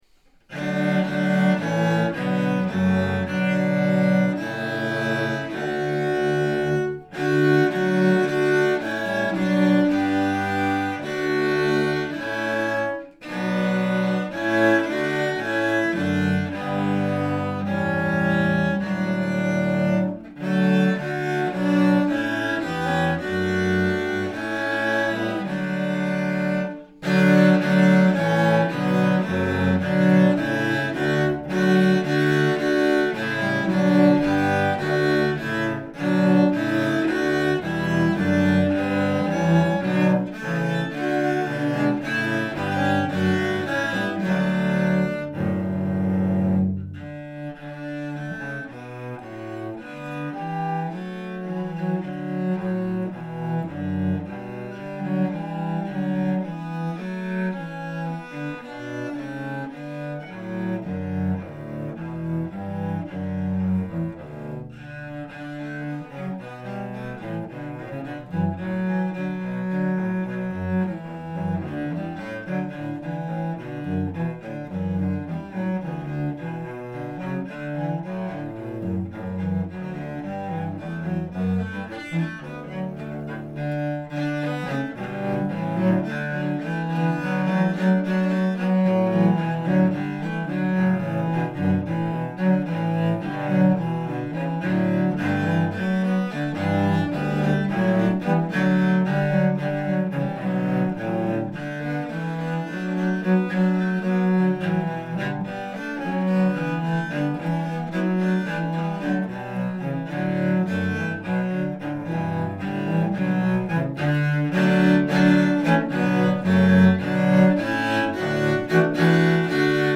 OLD HUNDREDTH is a ubiquitous hymn tune used widely since its 1561 publication in the Genevan Psalter. This arrangement for cello ensemble includes several traditional harmonizations and rhythmic settings. The inner verses alter the melody slightly in duet pairings with arpeggios. The arpeggios lead into a syncopated form of the melody in 7/8 time, which continues back to a four-part harmony with contemporary adjustments. Finally, the initial four-part harmony is repeated in 2/4.
OLD-HUNDREDTH-Cello-Ensemble.mp3